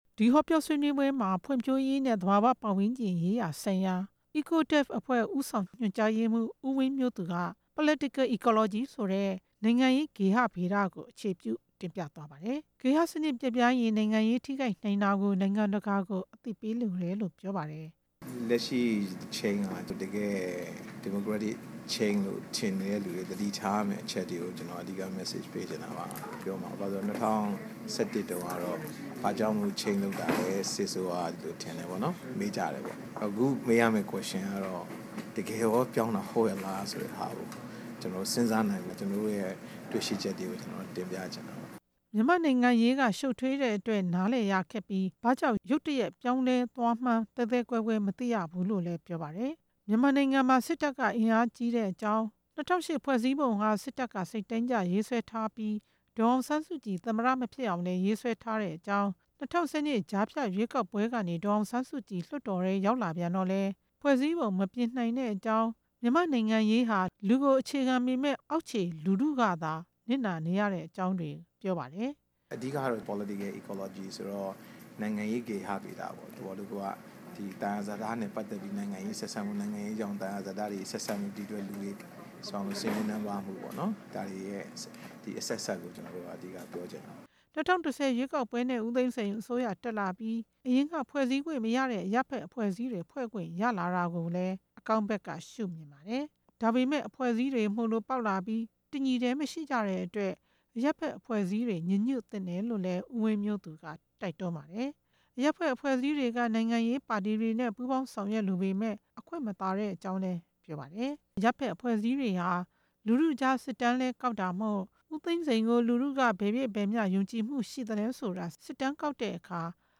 PISA- Partnership for International Strategies in Asia က ကမကထလုပ်ပြီး George Washington တက္ကသိုလ် မှာ Reform, Resource Governance and Civil Society in Myanmar မြန်မာနိုင်ငံ က ပြုပြင်ပြောင်းလဲခြင်း၊ သယံဇာတ စီမံခန့်ခွဲခြင်းနဲ့ အရပ်ဘက်အဖွဲ့အစည်းဆိုတဲ့ ဆွေးနွေးပွဲမှာ ပြောကြားသွား တာပါ။